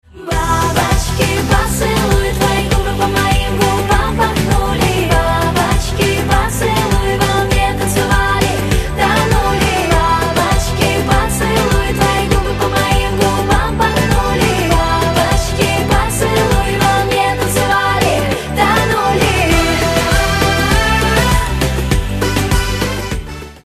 • Качество: 128, Stereo
поп
веселые